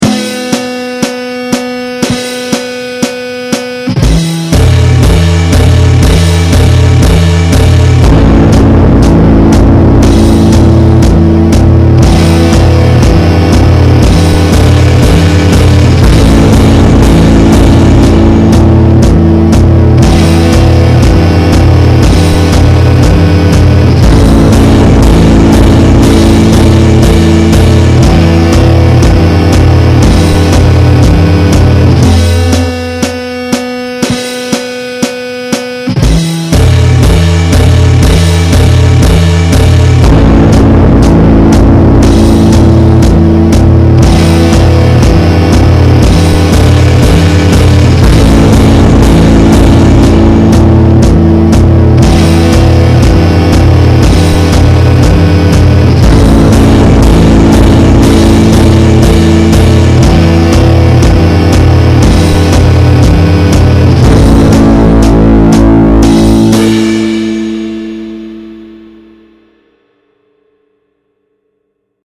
The Overture was recorded in 2003 as a sonic transcription of the visual image.